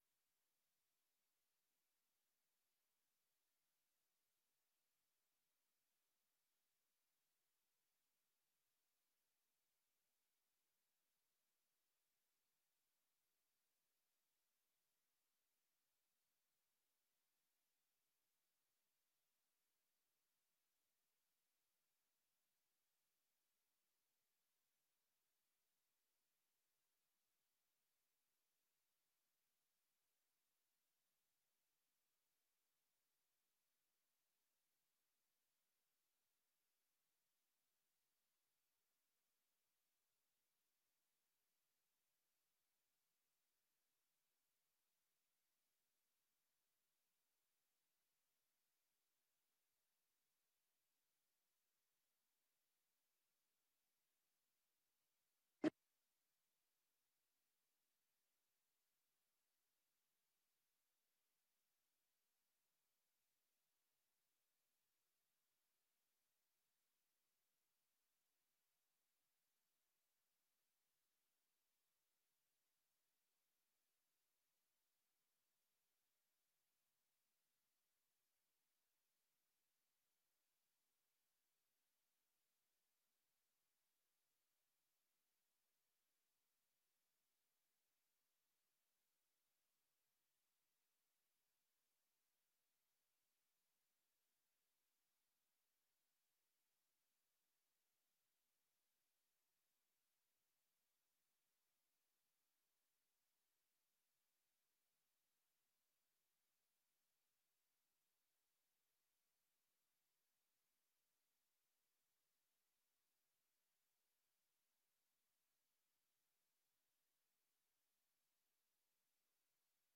Gemeenteraad 03 februari 2025 19:00:00, Gemeente Roosendaal
Locatie: Raadzaal Voorzitter: Jac Wezenbeek